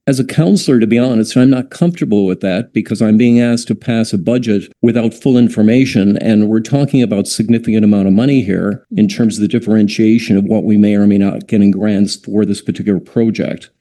Councillor Gary Waterfield said that came with a big question mark that was troubling.